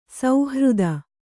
♪ sauhřda